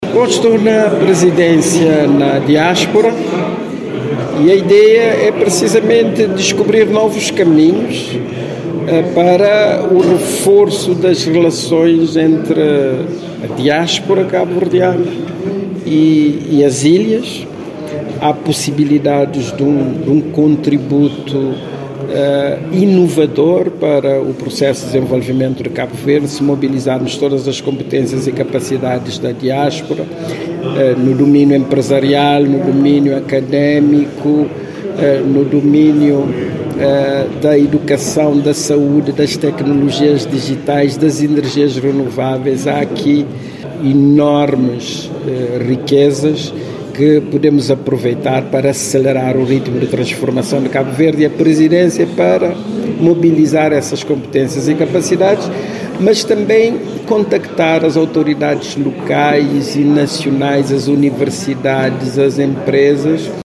O Presidente da República de Cabo Verde esteve ontem em Guimarães.